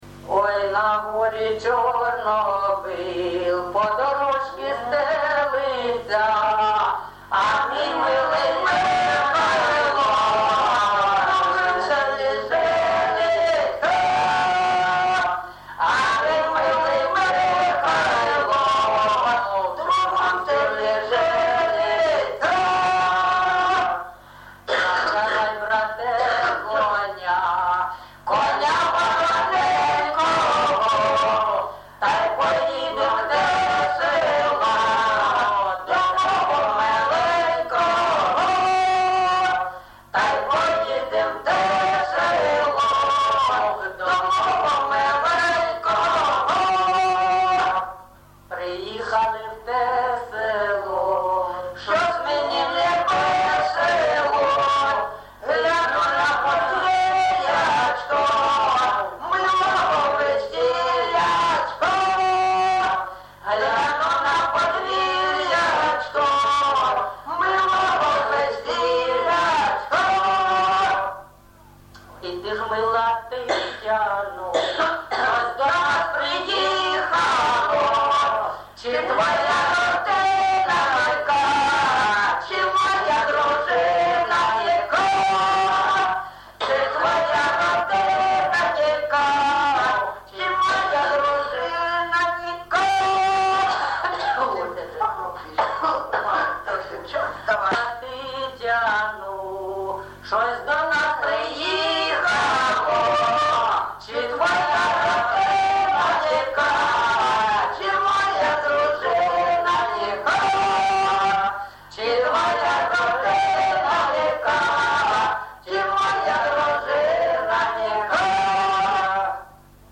ЖанрПісні з особистого та родинного життя
Місце записум. Єнакієве, Горлівський район, Донецька обл., Україна, Слобожанщина